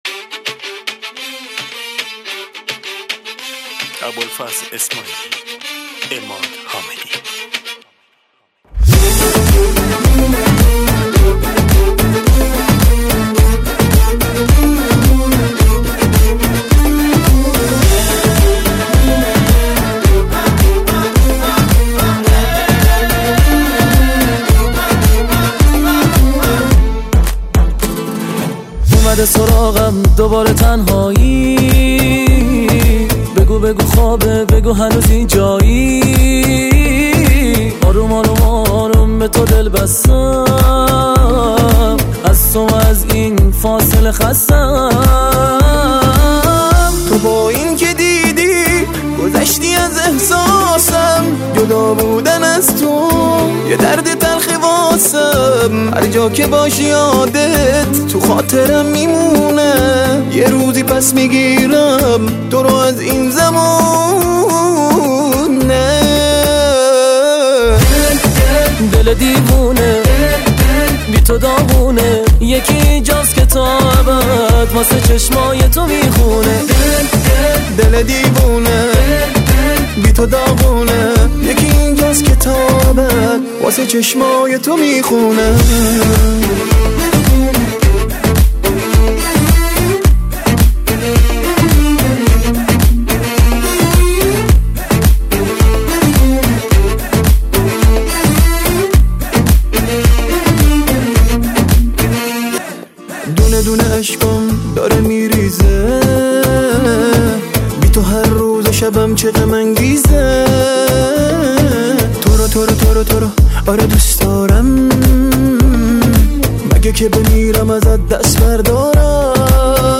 دانلود آهنگ شاد
Remix